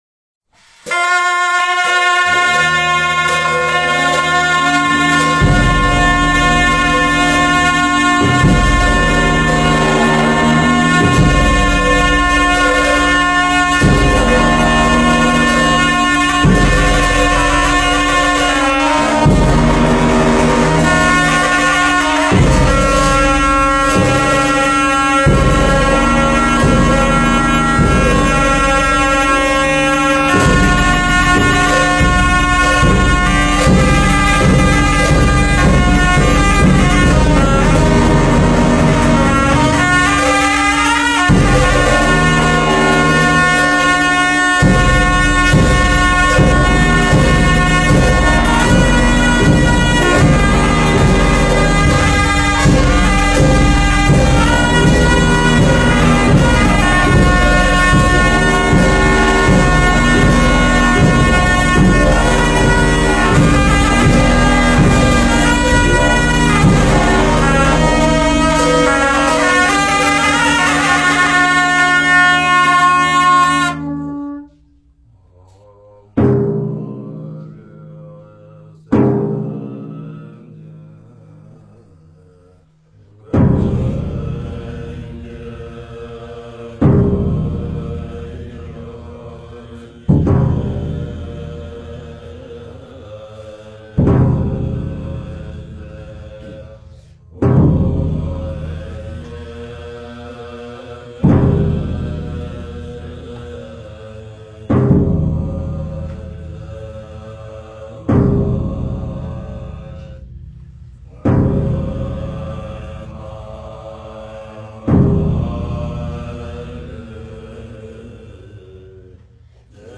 佛音 诵经 佛教音乐 返回列表 上一篇： 忿怒莲师法乐供赞之一(法乐